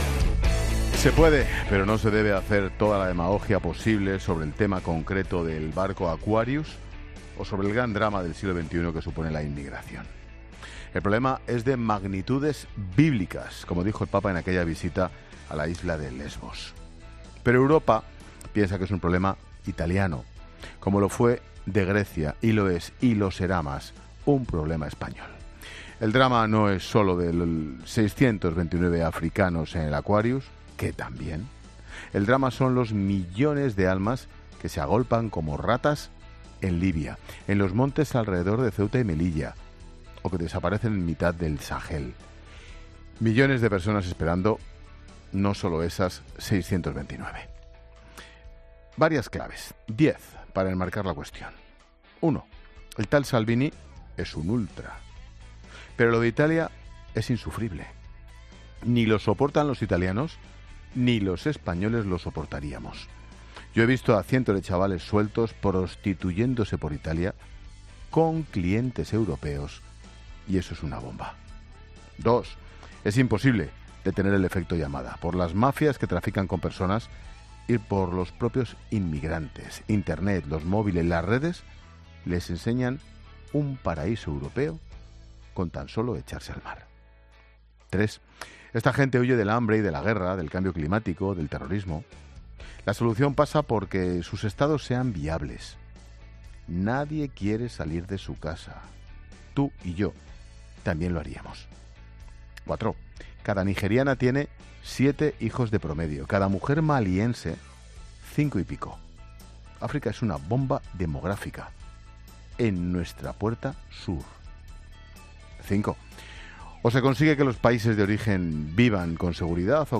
Monólogo de Expósito
El comentario de Ángel Expósito sobre la inmigración y el caso del Aquarius.